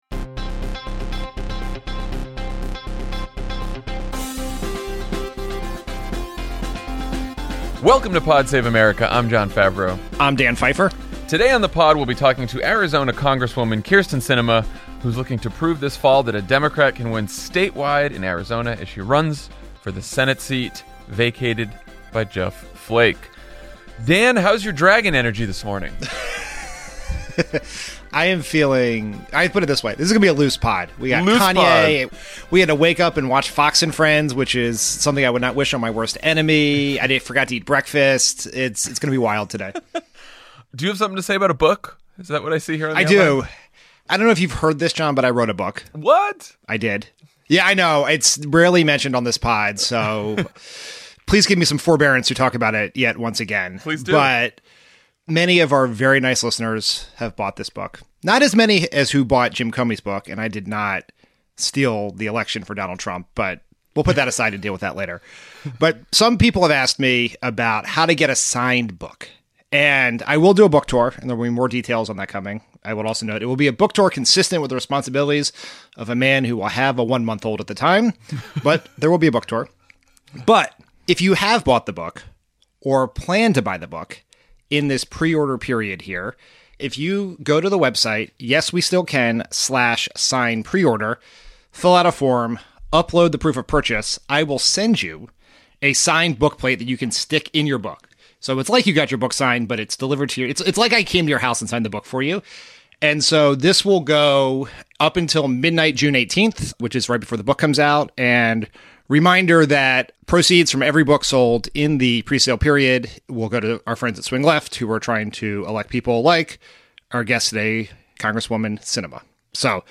Trump calls into his favorite program, Ronny Jackson withdraws, Mulvaney says the quiet part out loud, Kanye tweets, and Democrats find hope in a special election loss. Then Arizona Congresswoman Kyrsten Sinema joins Jon and Dan to talk about her Senate race and her approach to politics.